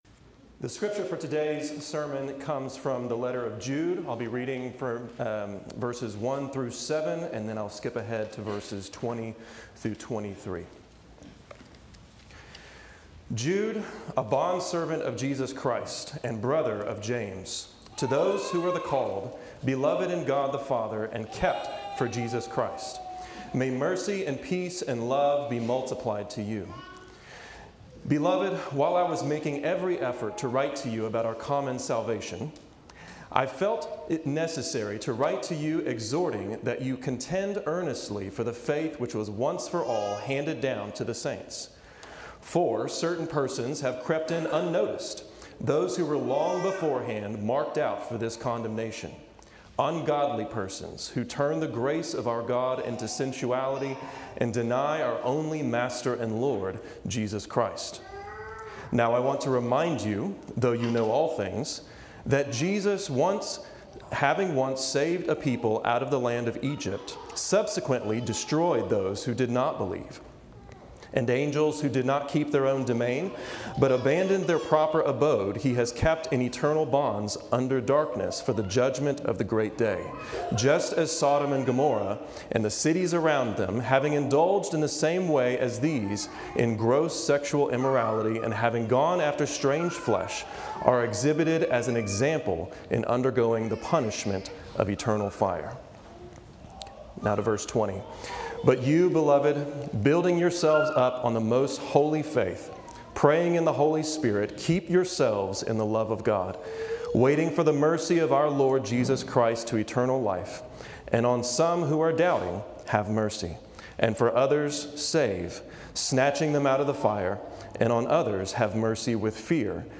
Passage: Jude 1:1-7; 20-23 Service Type: Sunday worship